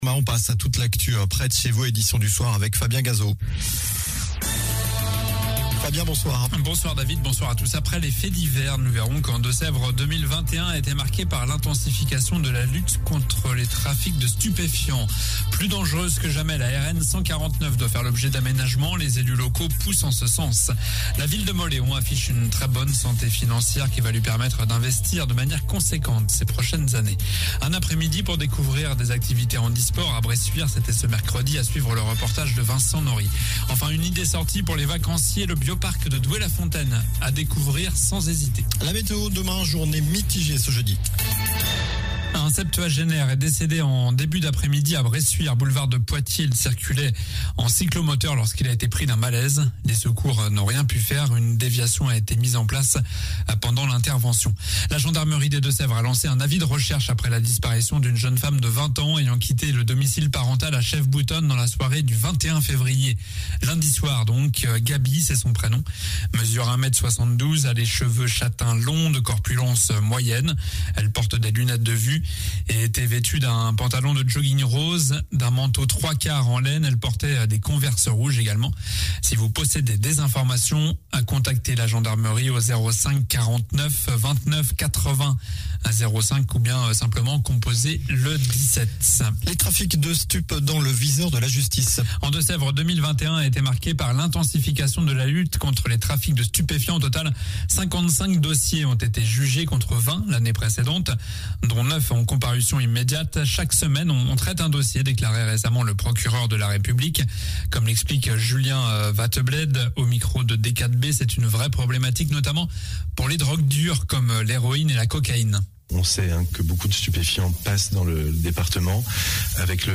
Journal du mercredi 23 février (soir)